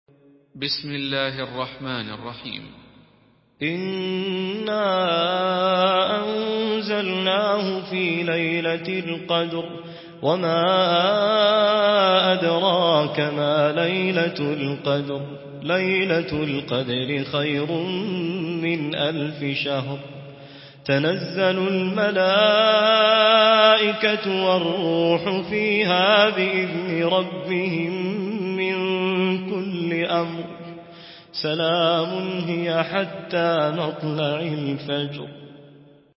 Murattal